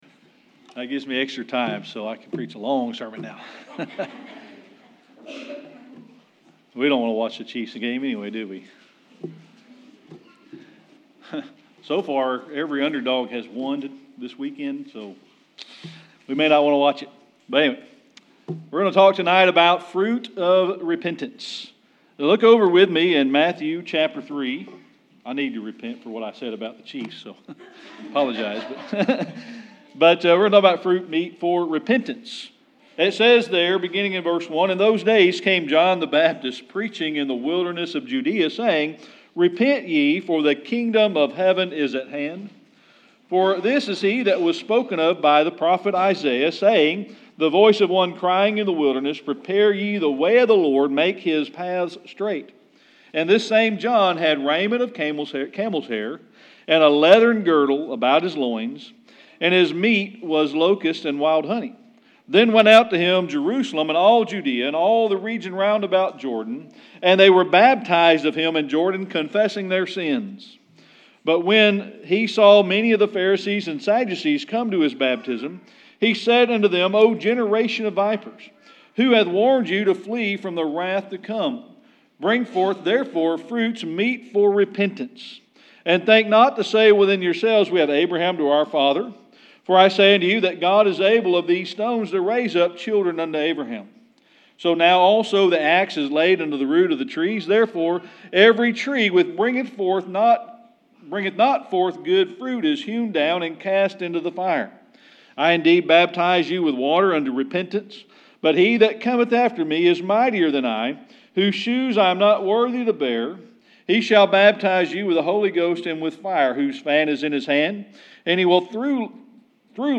Matthew 3:1-12 Service Type: Sunday Evening Worship We're going to talk tonight about fruit of repentance.